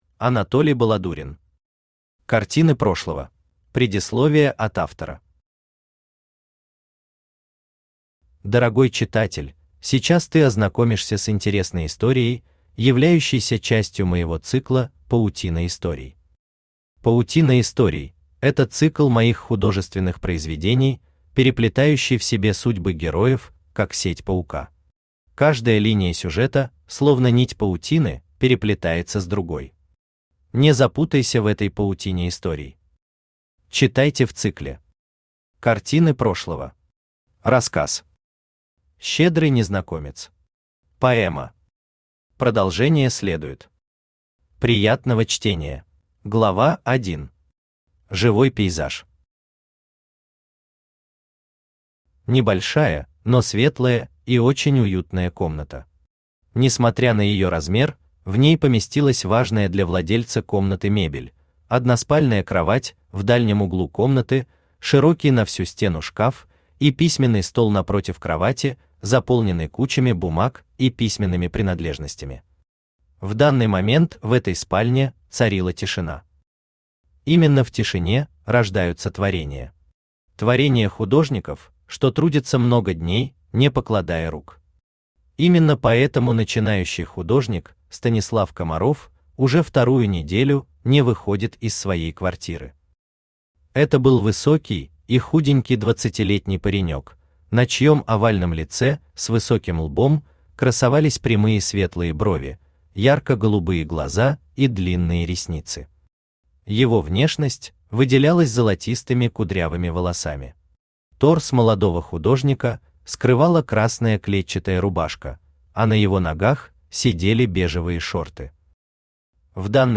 Аудиокнига Картины прошлого | Библиотека аудиокниг
Aудиокнига Картины прошлого Автор Анатолий Иванович Баладурин Читает аудиокнигу Авточтец ЛитРес.